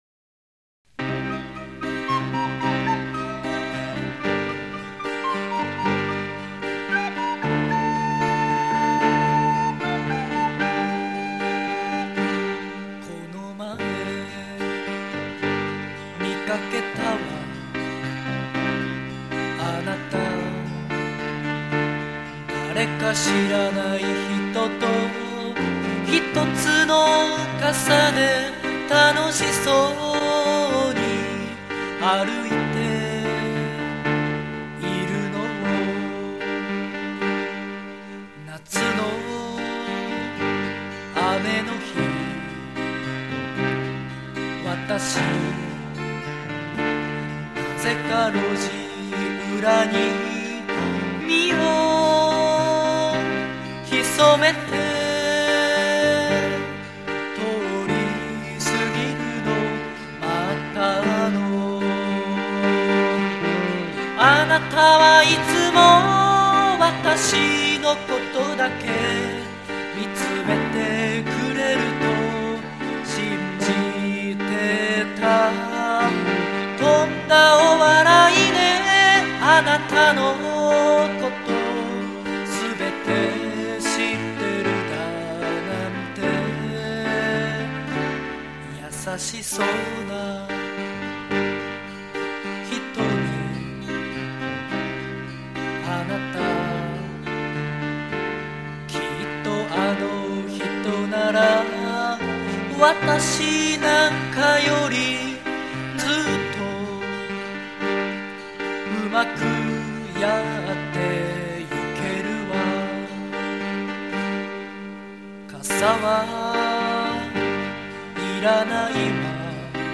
ボーカル・キーボード・リコーダー
ベース・ギター
この曲は完全に演歌です。